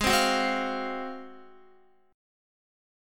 G#13 Chord
Listen to G#13 strummed